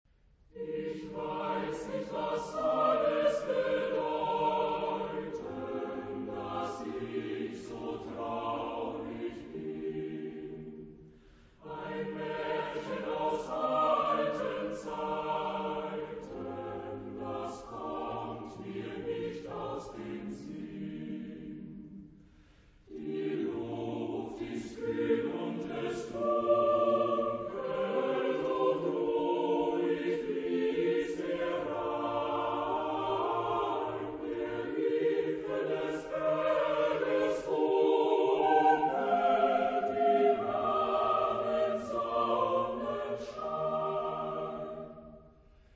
Genre-Style-Forme : Lied ; Profane
Type de choeur : SATB  (4 voix mixtes )
Tonalité : mi bémol majeur